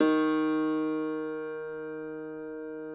53l-pno07-D1.aif